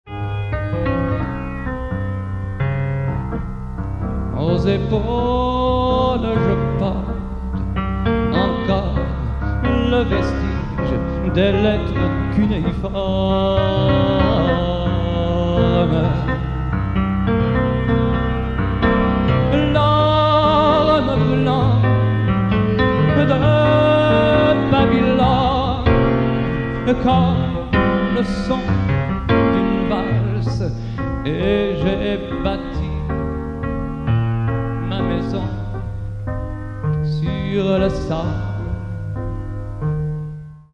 Chansonzyklus
Bass